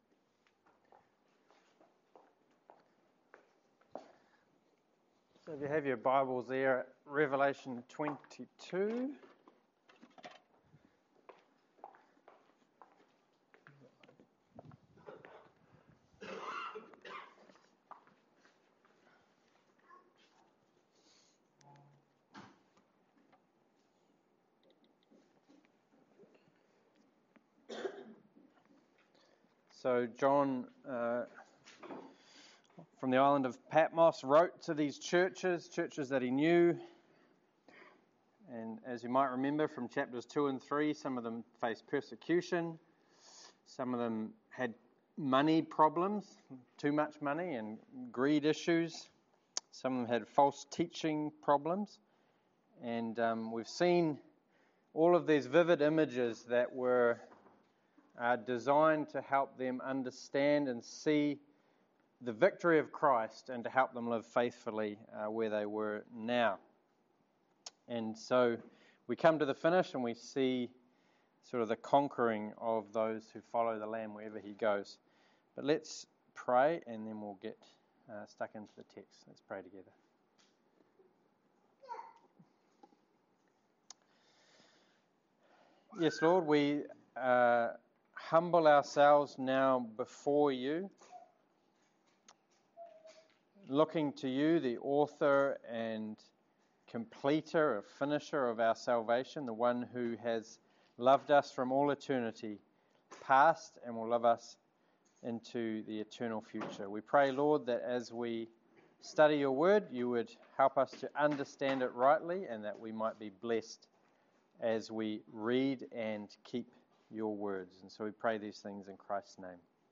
Revelation 22:6-21 Service Type: Sermon The closing words of the bible end with a promise for Jesus- that he is coming.